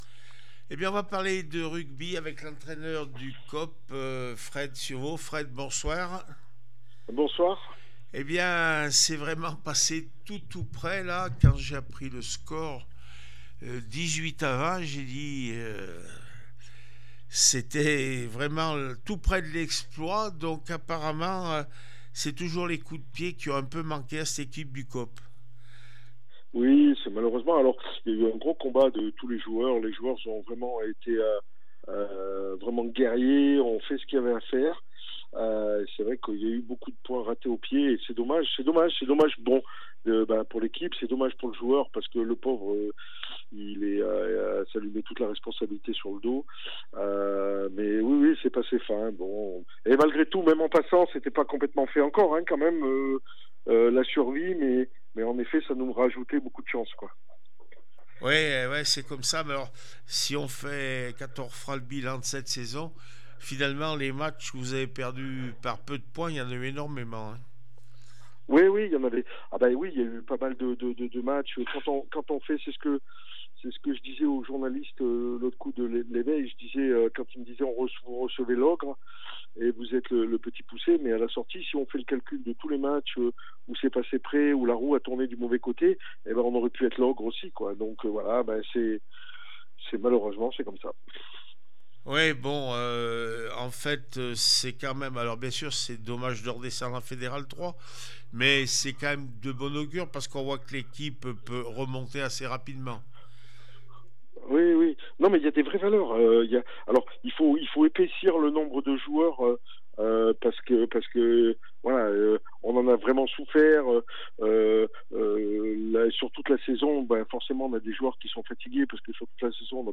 14 avril 2025   1 - Sport, 1 - Vos interviews